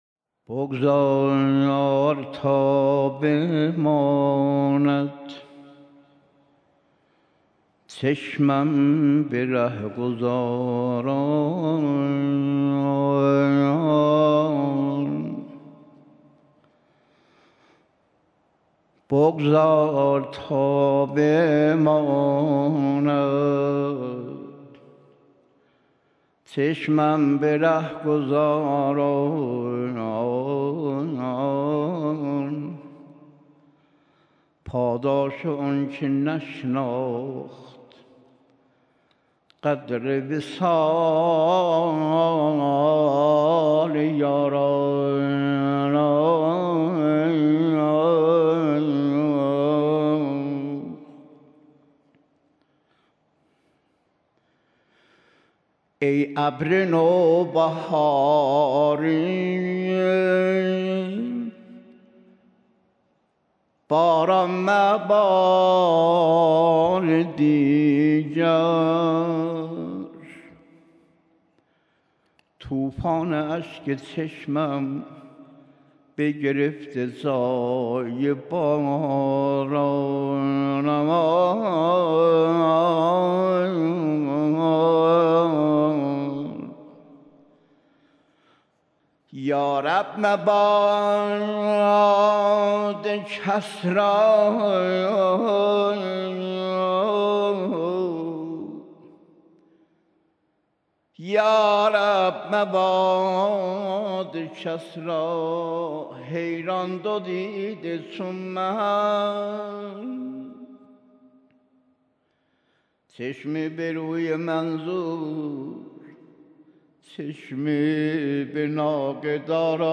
آخرین شب مراسم عزاداری حضرت فاطمه‌زهرا سلام‌الله‌علیها
مداحی